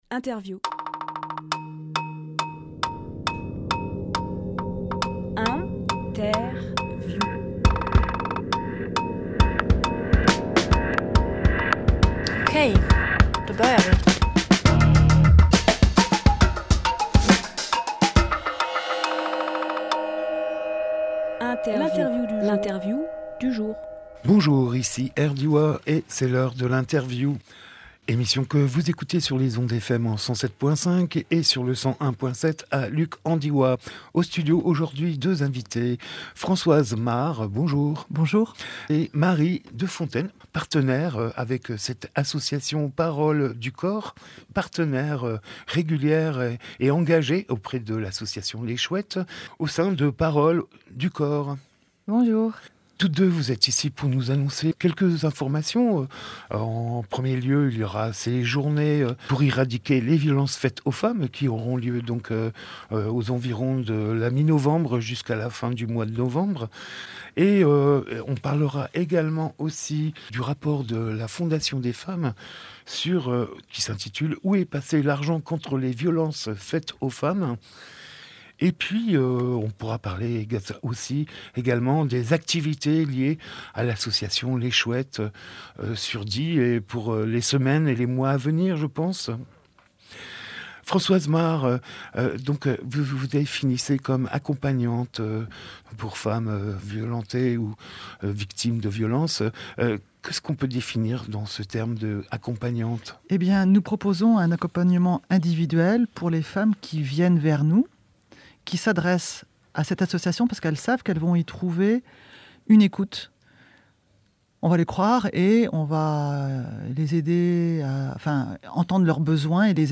Emission - Interview Les Chouettes contre les violences faites aux femmes Publié le 7 octobre 2023 Partager sur…
04.10.23 Lieu : Studio RDWA Durée